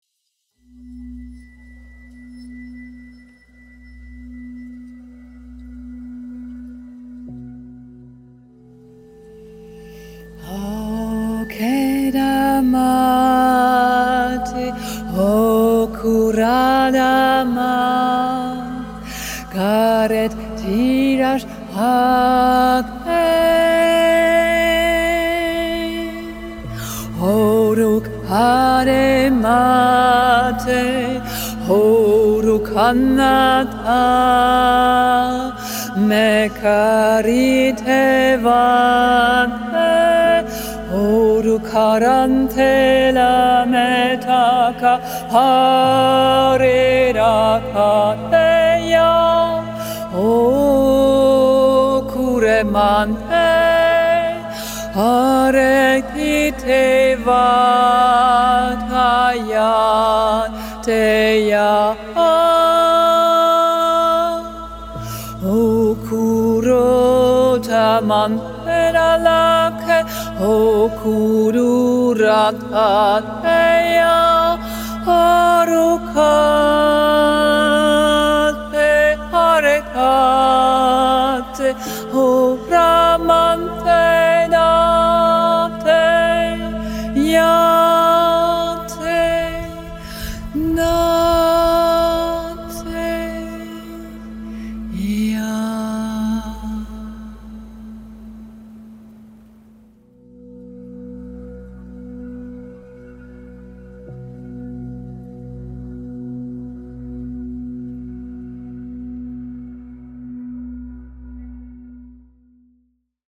Boek je persoonlijke Vocal Sound Healing
Mijn PowerBooster (Healing Frequencies voor verschillende onderwerpen) helpen je om binnen enkele minuten je energieniveau te veranderen, je mentale gezondheid te versterken, stress te verminderen en nieuwe kracht te vinden.